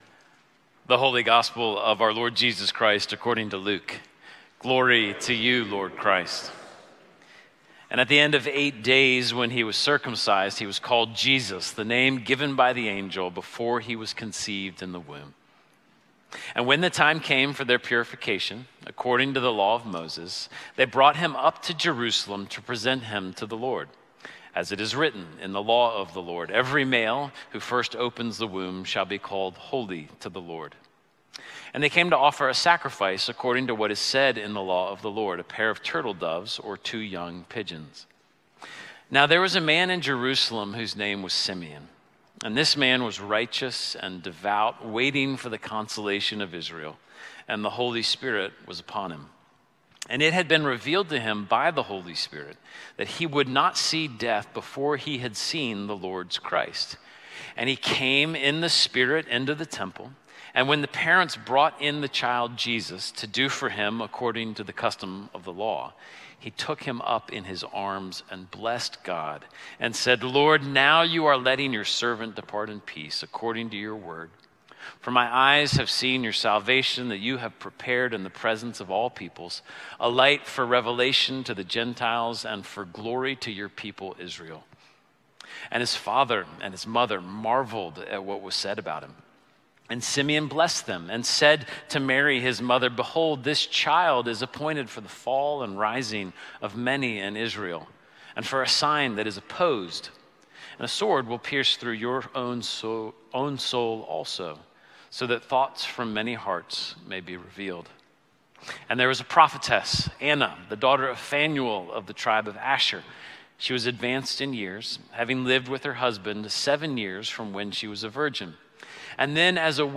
Sermon-January-5-2025.mp3